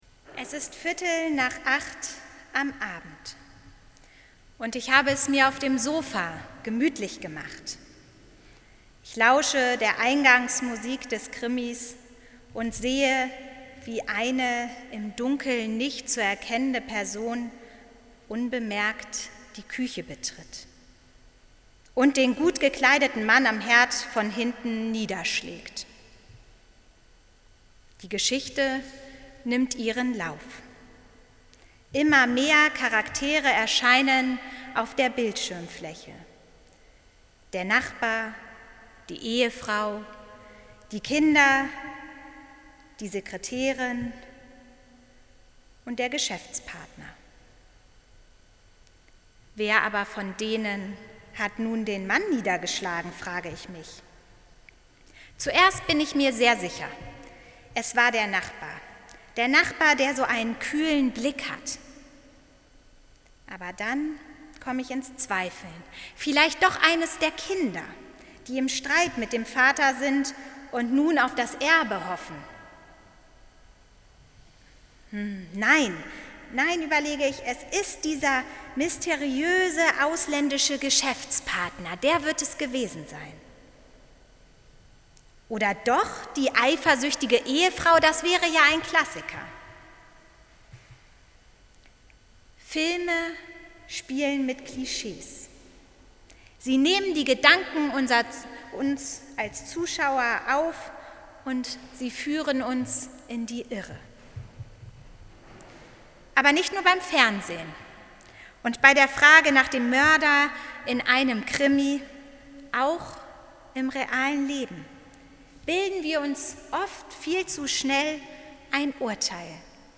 Predigt zum Sonntag